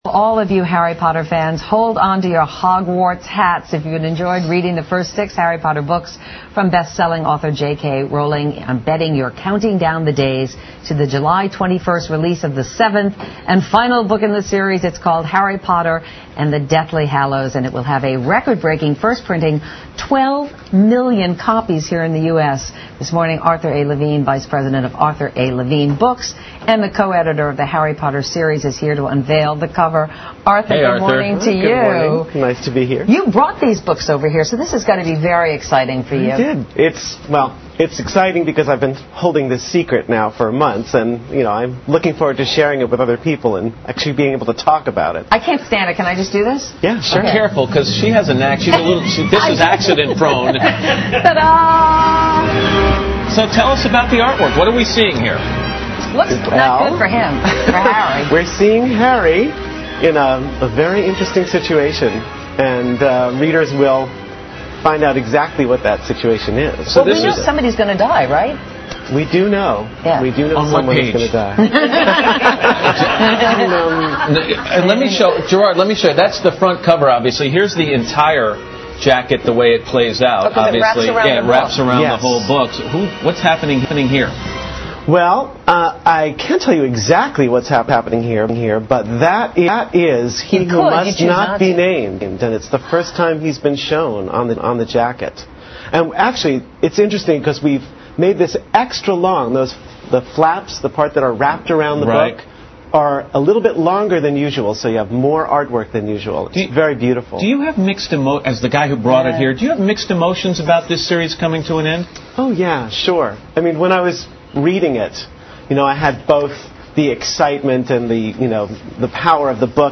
访谈录 Interview 2007-03-31&0402, 抢先揭开最后一部哈里波特面纱 听力文件下载—在线英语听力室